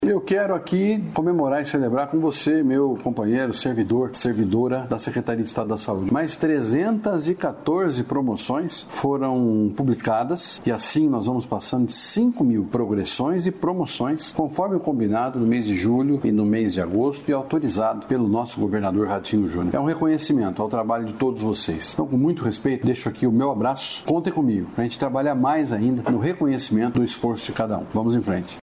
Sonora do secretário da Saúde, Beto Preto, sobre promoção de 4,5 mil servidores da saúde